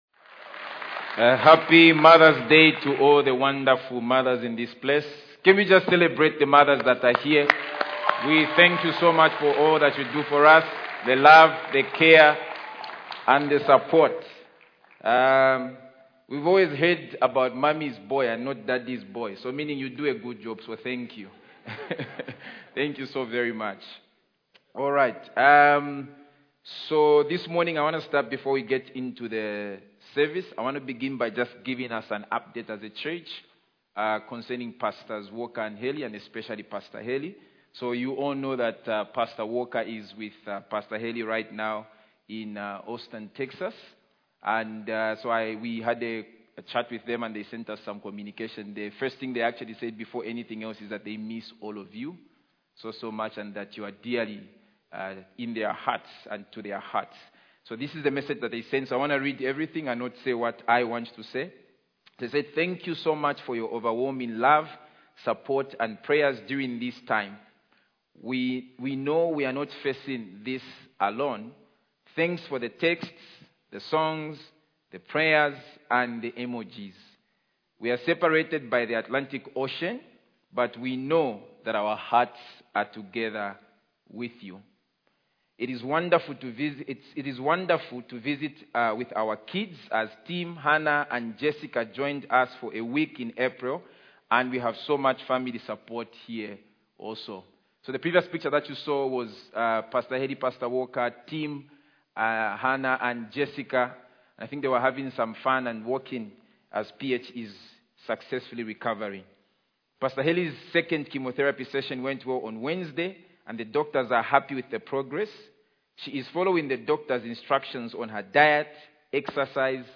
A message from the series "True Wisdom."